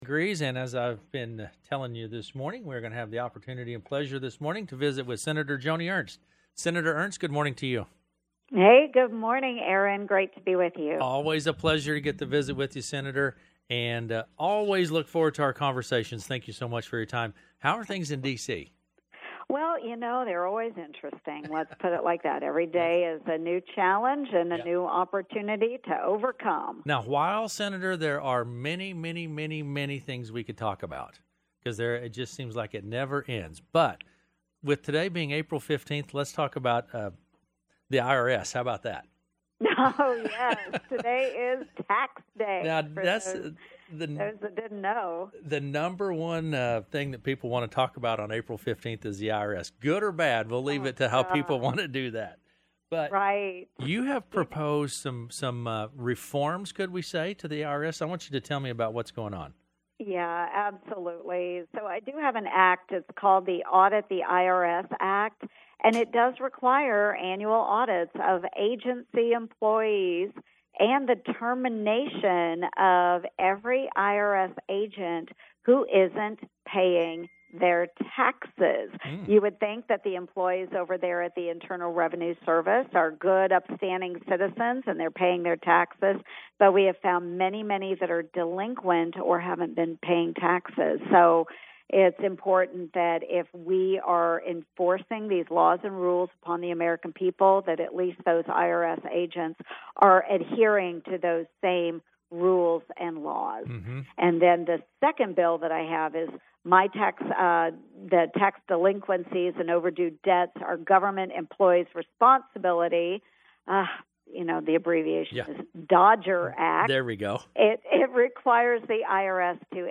U.S. Senator Joni Ernst joined KCSI Radio Tuesday morning to discuss federal tax accountability, military family support, and an upcoming Iowa business event